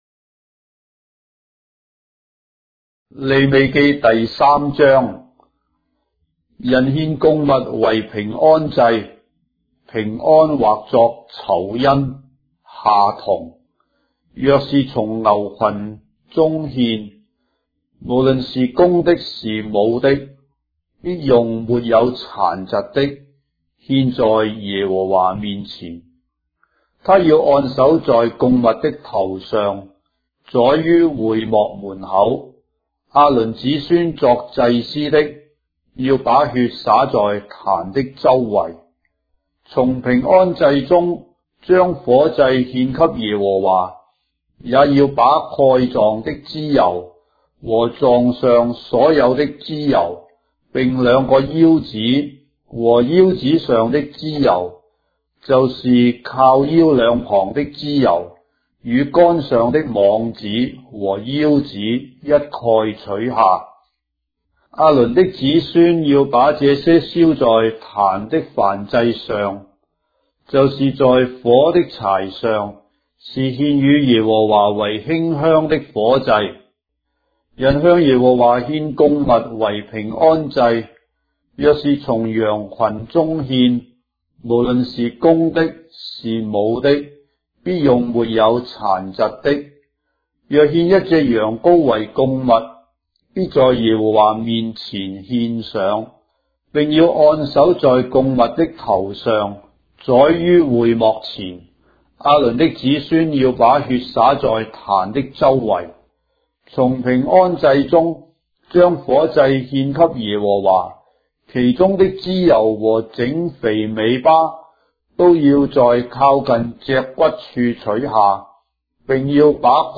章的聖經在中國的語言，音頻旁白- Leviticus, chapter 3 of the Holy Bible in Traditional Chinese